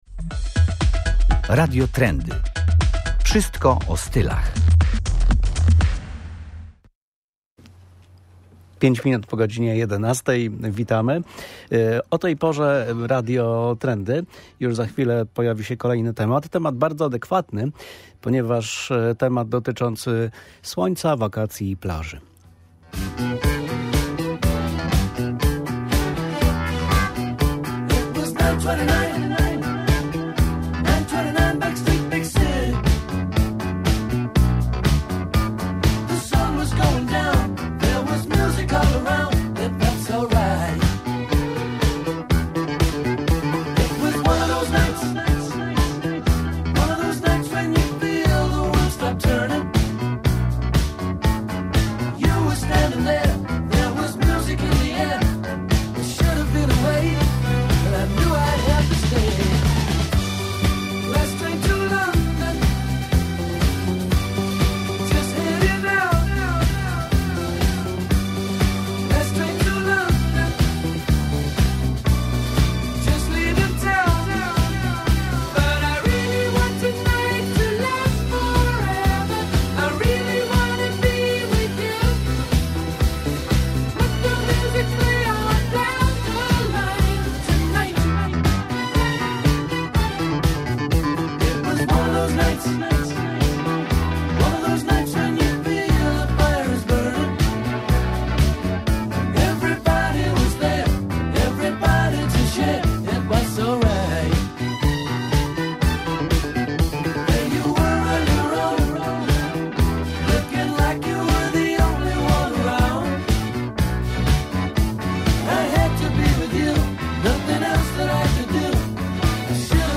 Stąd też wizyta na campingu przy plaży w Sopocie.